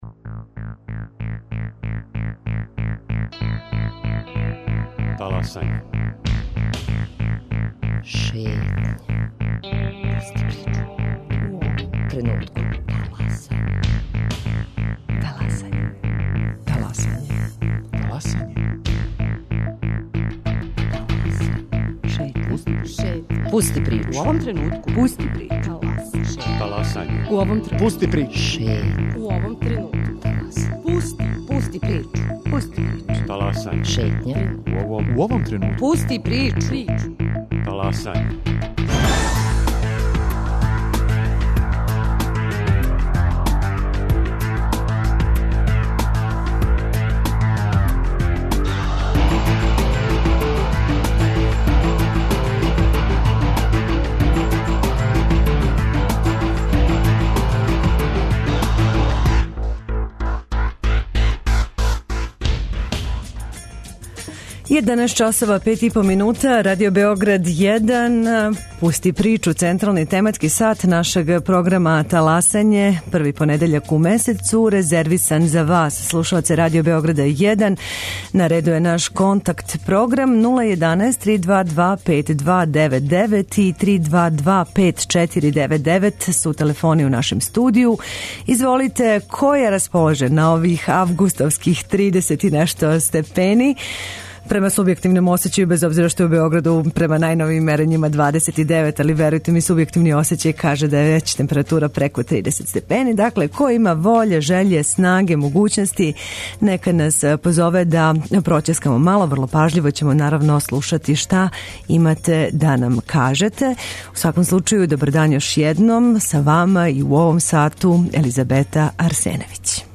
Августовска врелина није никакав разлог да се и данас не дружимо у КОНТАКТ-ПРОГРАМУ РАДИО БЕОГРАДА 1 !